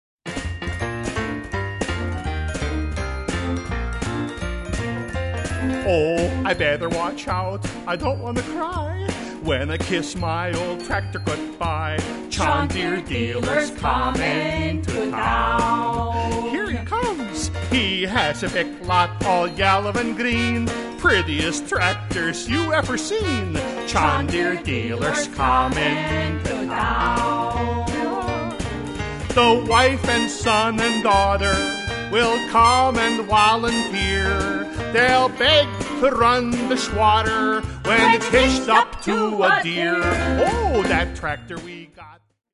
--funny Christmas song parodies